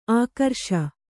♪ ākarṣa